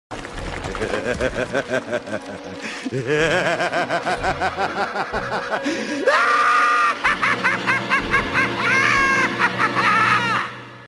Play, download and share BTAS Joker Laugh original sound button!!!!
btas-joker-laugh.mp3